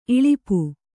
♪ iḷipu